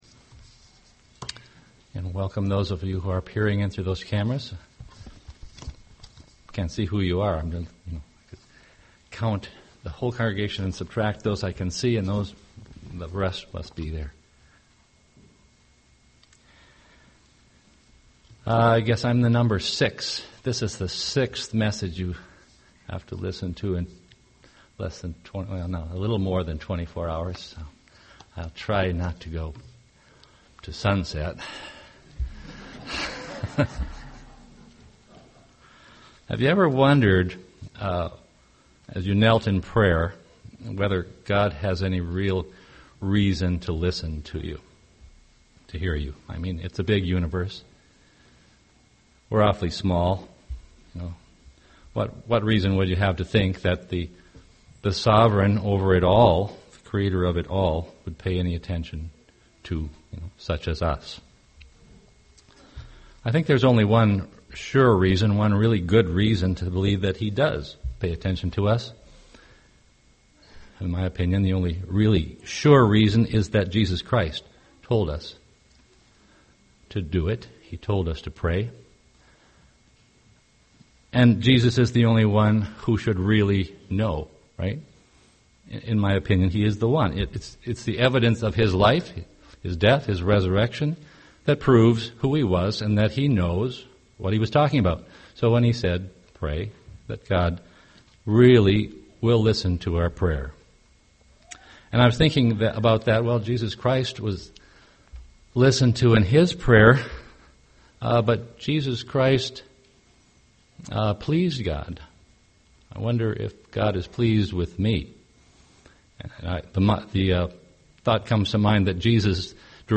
UCG Sermon pleasing God Studying the bible?